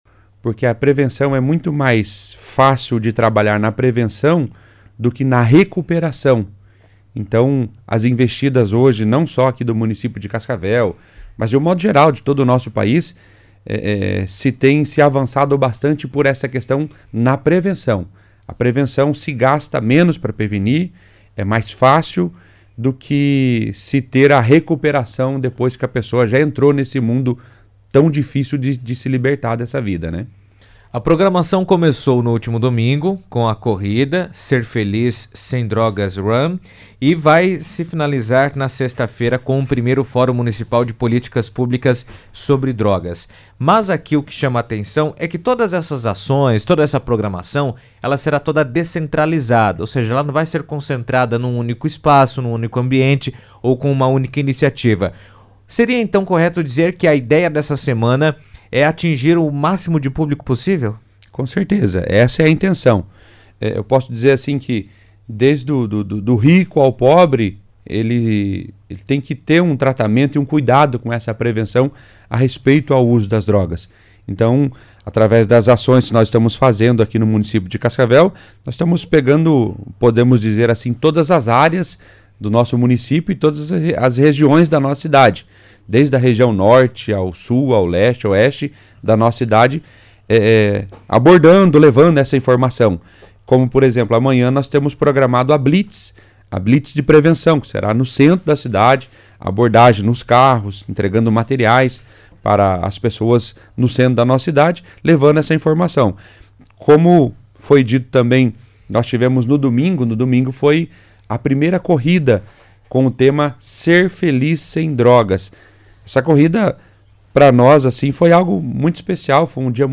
estiveram na CBN Cascavel e deram detalhes da programação desta semana.